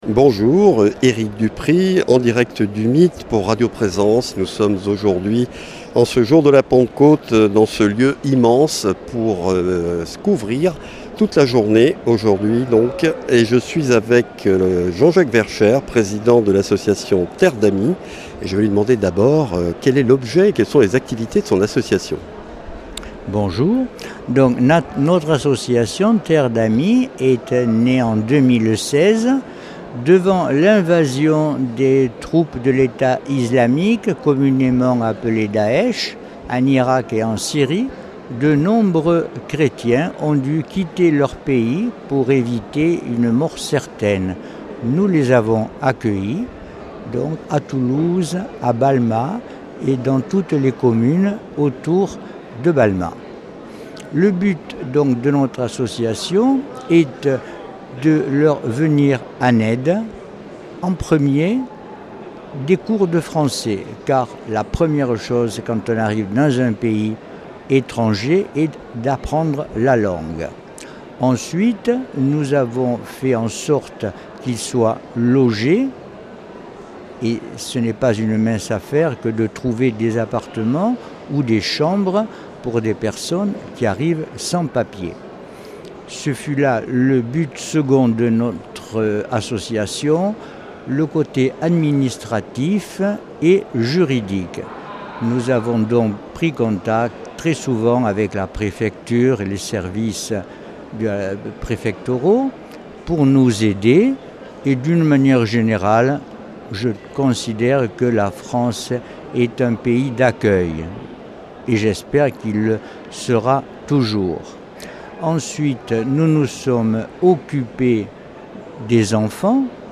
Ensemble, ils évoquent l’engagement de l’association auprès des migrants, des demandeurs d’asile. Il met l’accent sur la place de la fraternité au cœur de cette démarche. Un échange éclairant, ancré dans l’espérance et la solidarité.